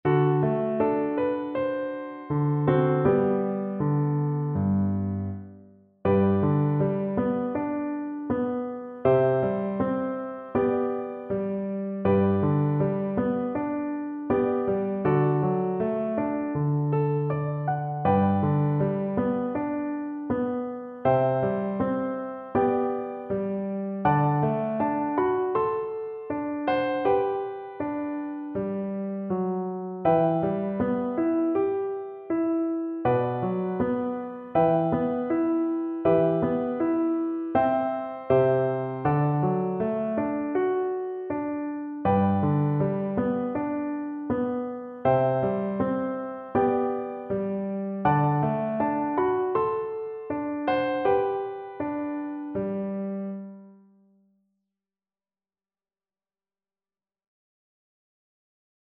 Andante
F#5-G6
Chinese